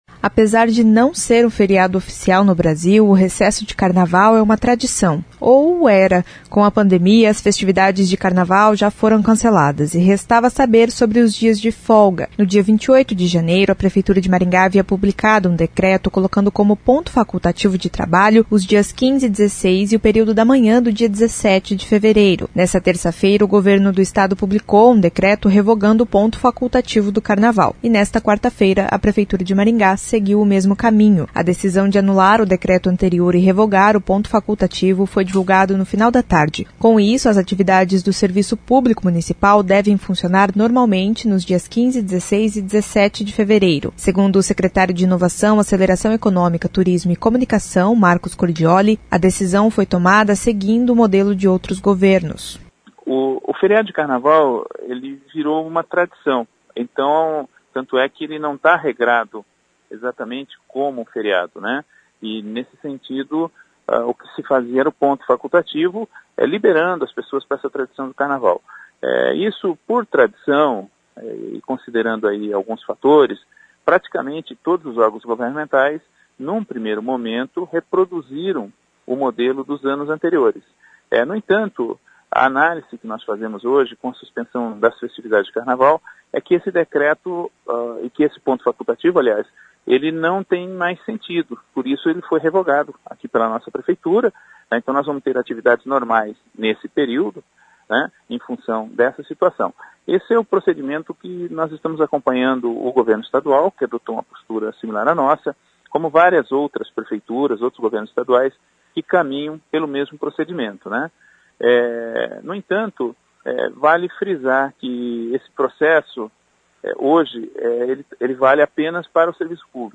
Segundo o secretário de Inovação, Aceleração Econômica, Turismo e Comunicação (Siacom), Marcos Cordiolli, a decisão foi tomada seguindo outros governos. [ouça no áudio acima]